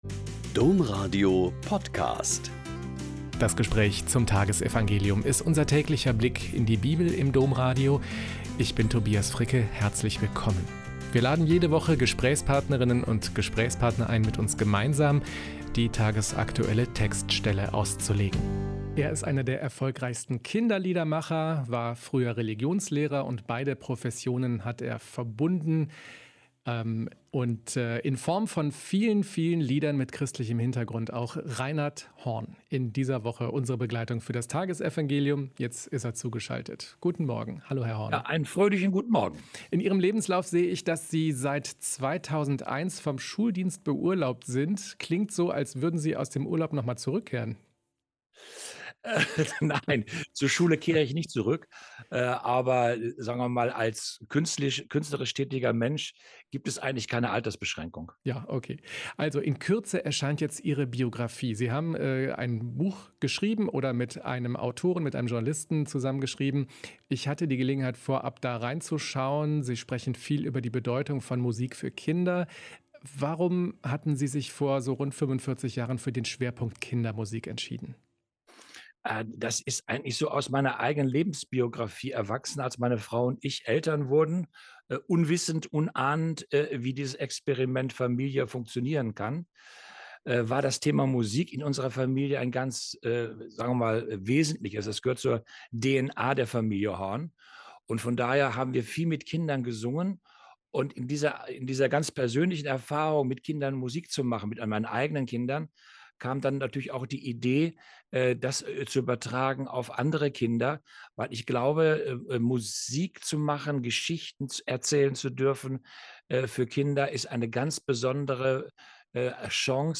Joh 5,1-16 - Gespräch